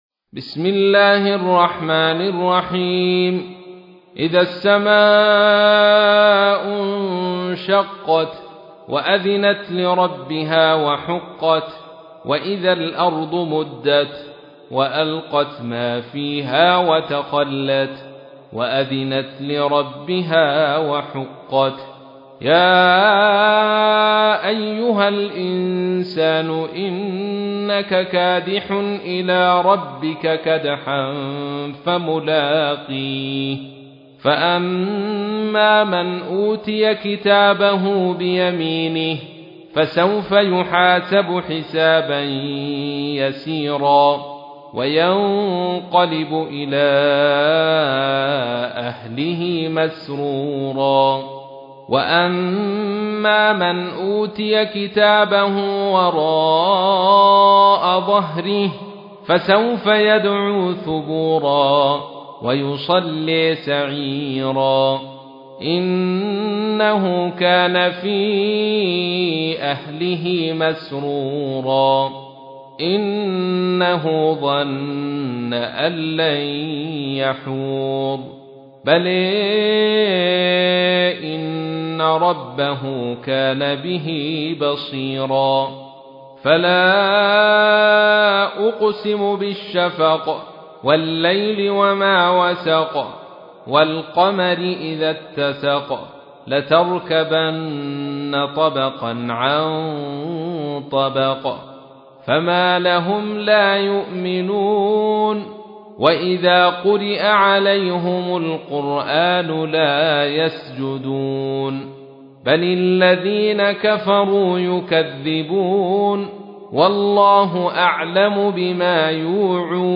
تحميل : 84. سورة الانشقاق / القارئ عبد الرشيد صوفي / القرآن الكريم / موقع يا حسين